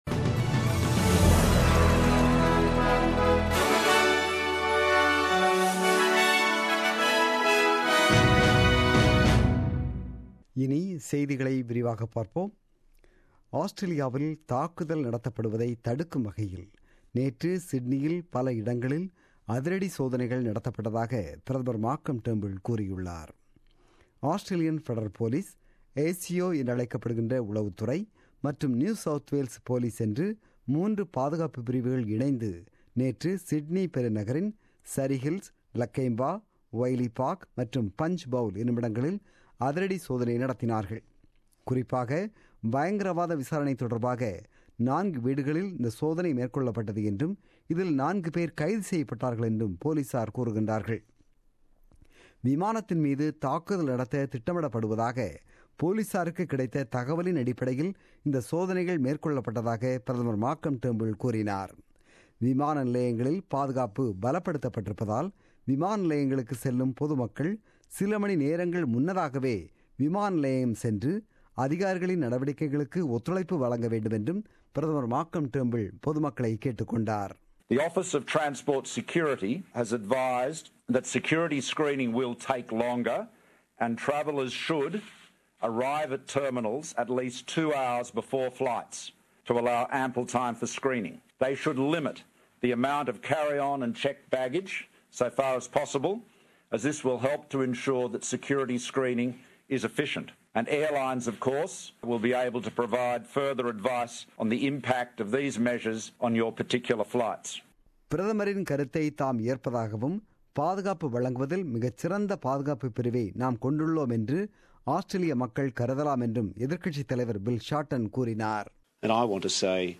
The news bulletin broadcasted on 30 July 2017 at 8pm.